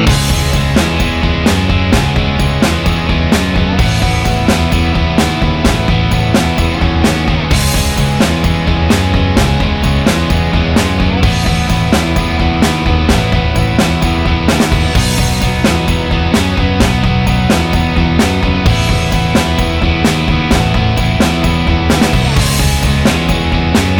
Minus Lead Rhythm Guitar Rock 4:20 Buy £1.50